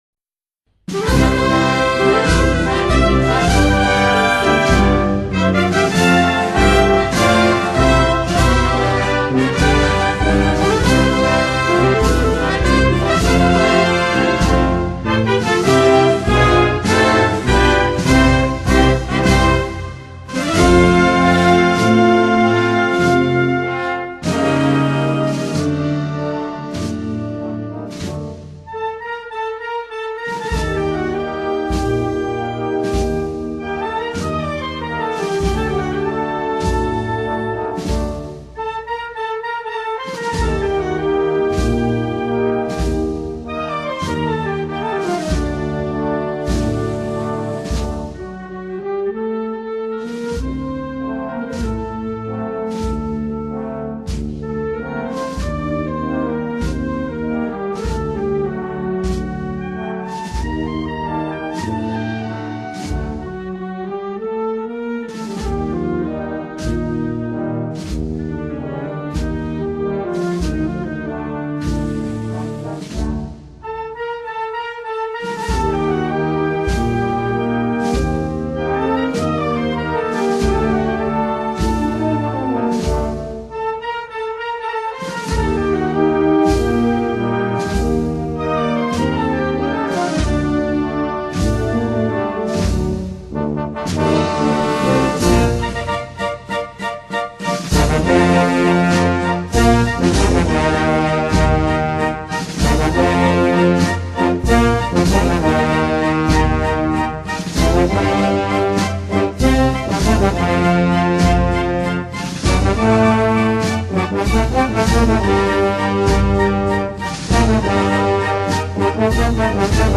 XXXIII acto de Exaltación a Nuestra Señora de la Encarnación
El acto se inició con la interpretación por la Banda Municipal de la Puebla del Río de la marcha «